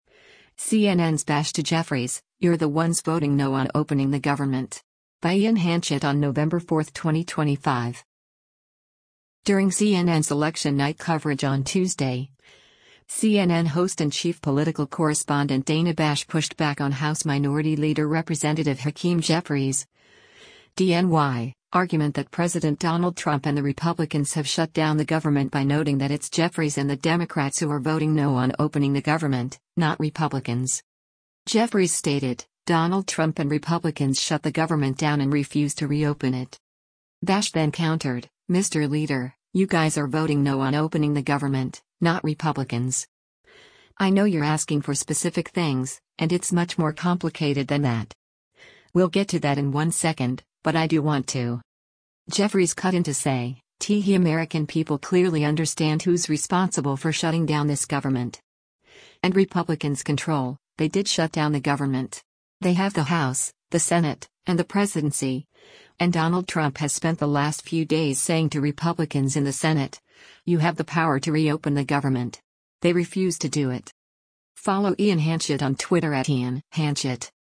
During CNN’s Election Night coverage on Tuesday, CNN host and Chief Political Correspondent Dana Bash pushed back on House Minority Leader Rep. Hakeem Jeffries’ (D-NY) argument that President Donald Trump and the Republicans have shut down the government by noting that it’s Jeffries and the Democrats who “are voting no on opening the government, not Republicans.”